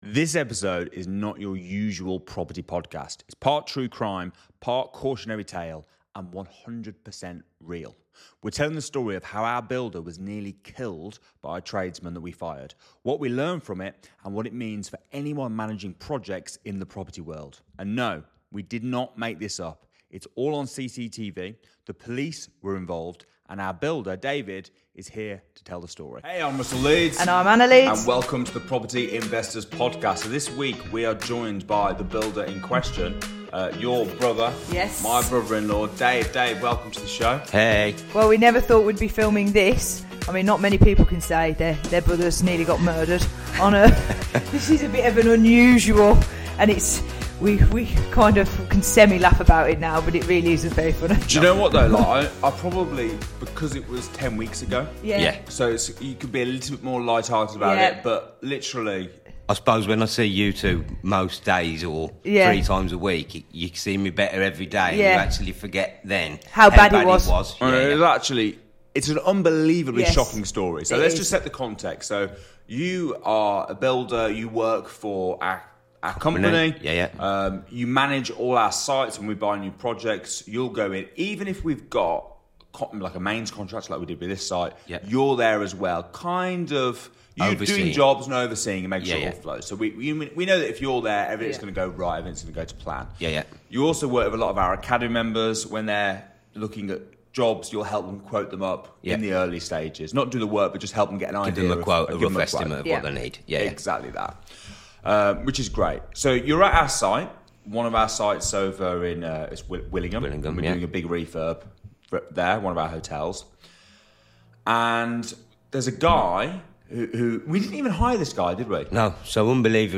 The discussion also expands on valuable lessons for managing property projects , hiring builders, and ensuring safety on-site, offering insightful tips for property investors.